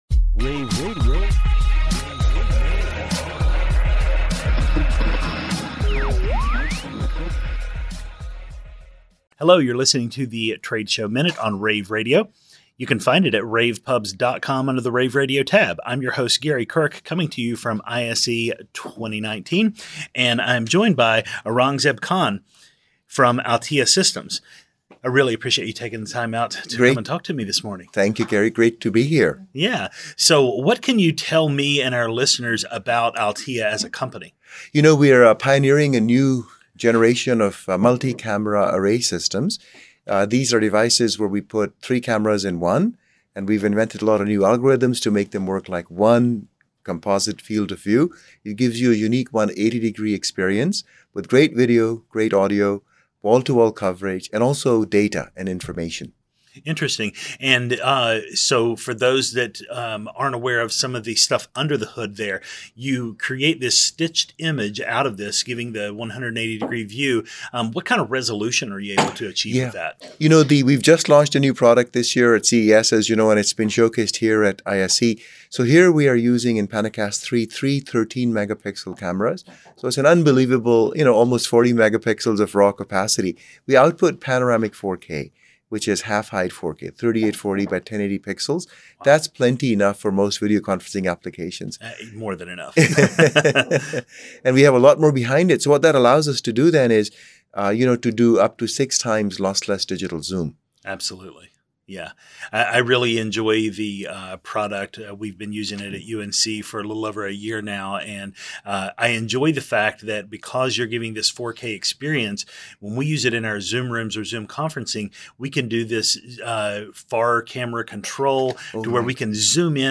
February 7, 2019 - ISE, ISE Radio, Radio, rAVe [PUBS], The Trade Show Minute,